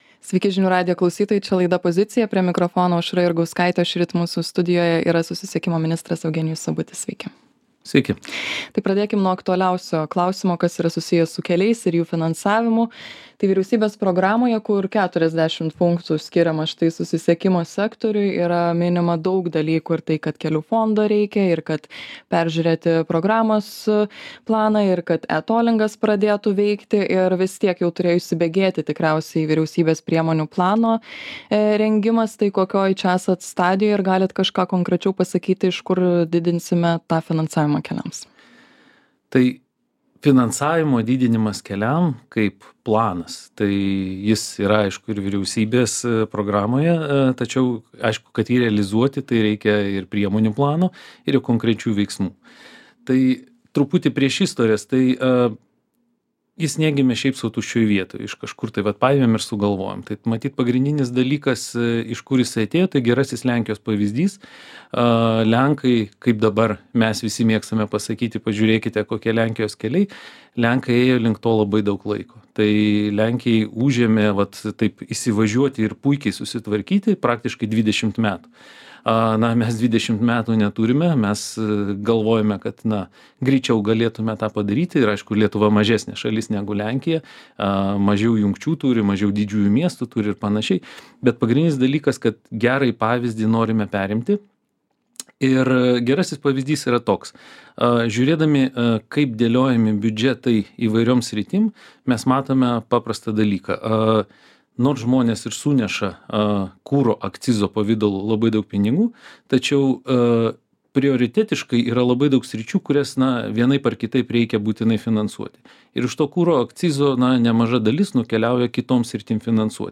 Pokalbis su susisiekimo ministru Eugenijumi Sabučiu.